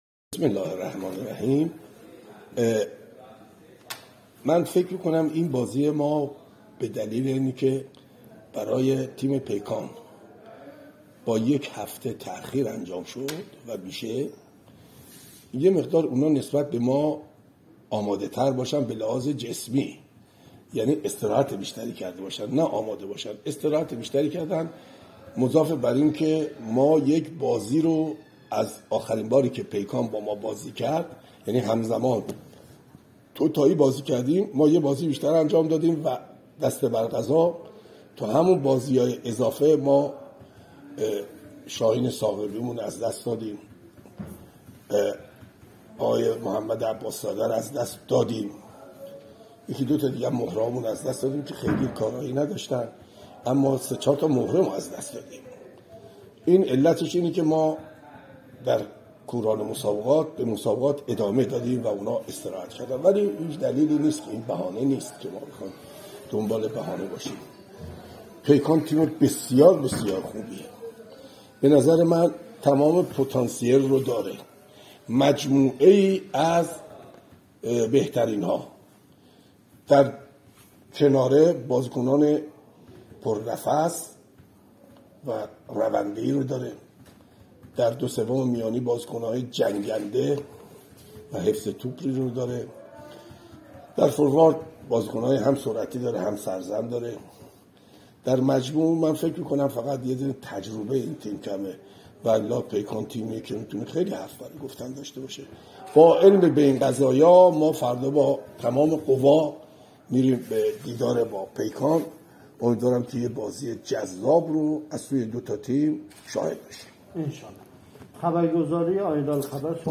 صحبت های فیروز کریمی سرمربی تراکتور
نشست خبری سرمربی تراکتور پیش از بازی مقابل پیکان از هفته چهارم لیگ برتر فوتبال ایران